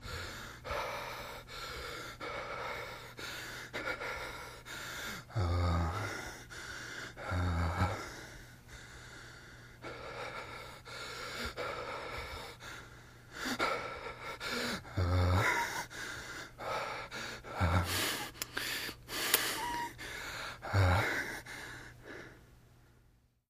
HeavyMaleBreathing AZ175702
Heavy Male Breathing, W Wheezes And Moans. 2